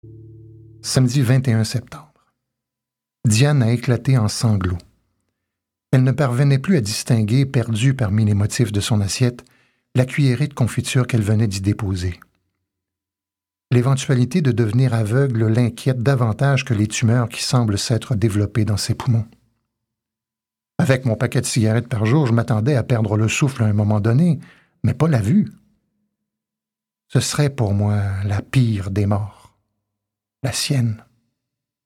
recit